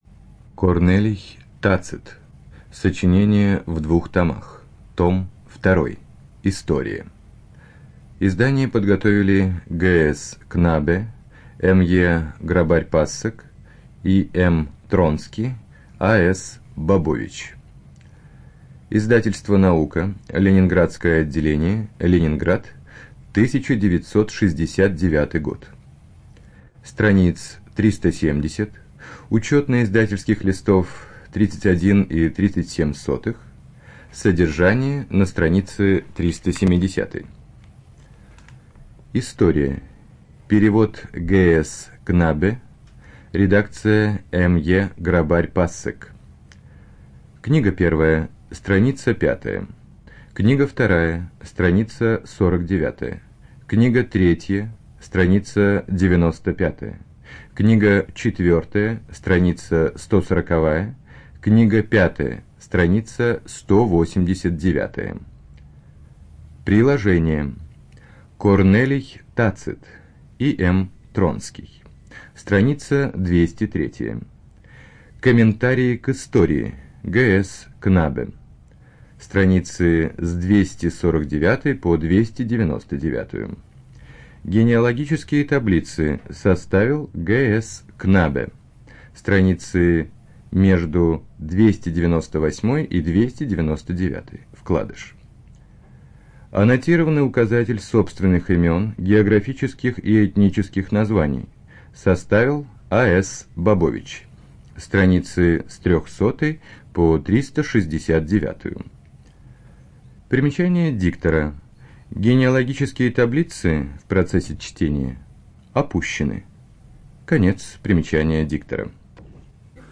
Студия звукозаписиРоссийская государственная библиотека для слепых